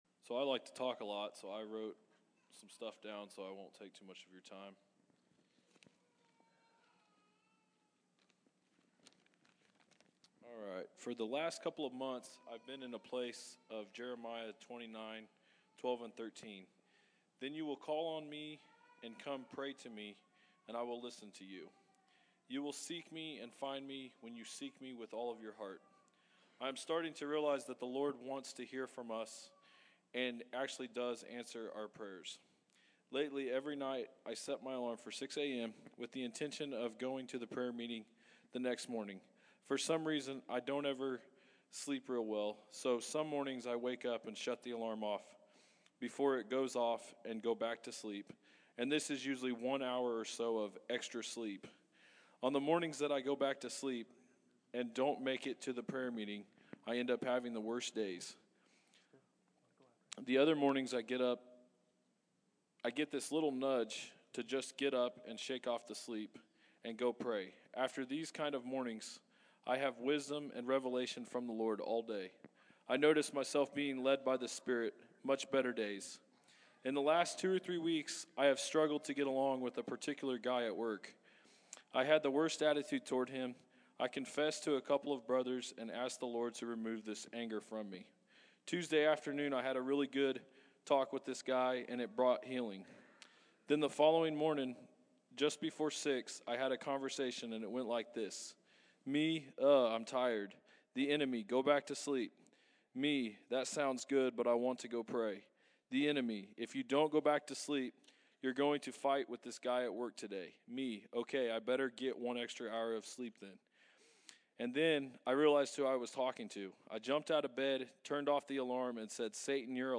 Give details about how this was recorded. November 29, 2015 Category: Testimonies | Location: El Dorado